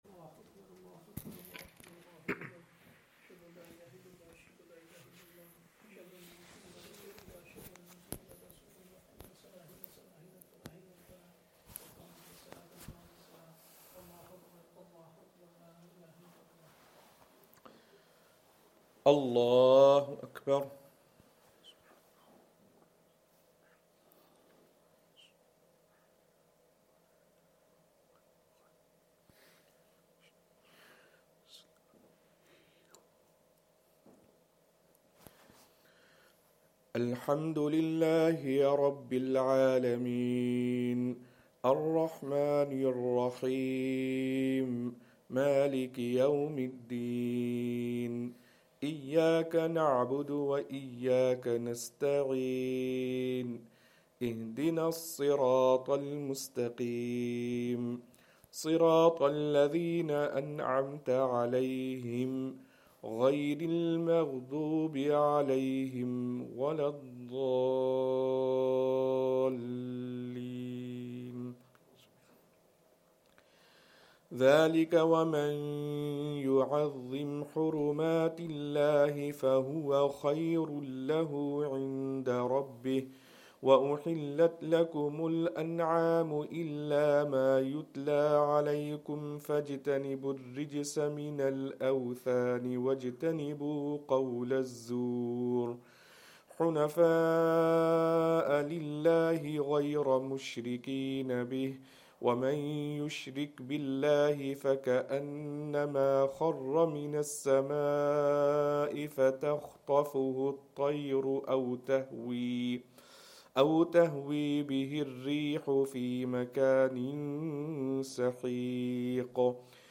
eMasjid Live is an online streaming platform for Masaajids and Islamic radio stations. eMasjid Live has been built to provide an alternative to traditional analogue receivers whilst allowing users to listen to their local and UK wide Masaajids online wherever they are.
Madni Masjid, Langside Road, Glasgow